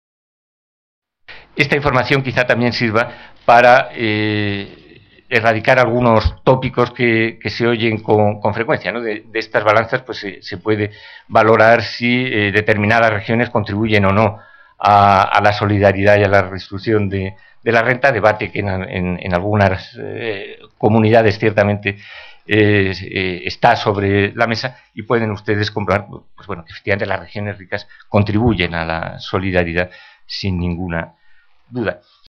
Declaracions de Carlos Ocaña, secretari d’estat d’Hisenda: Ocaña1,